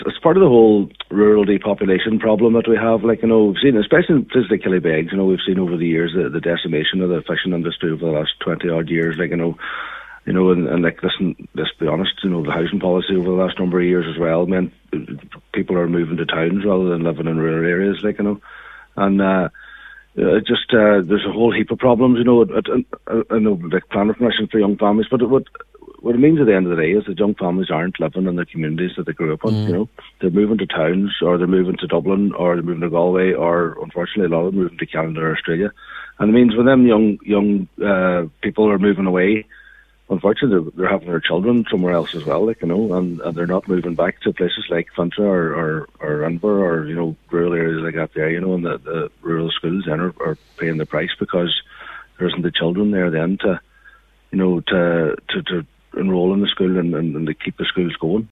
Cllr Jimmy Brogan says its largely down to rural depopulation: